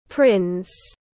Hoàng tử tiếng anh gọi là prince, phiên âm tiếng anh đọc là /prɪns/.
Prince /prɪns/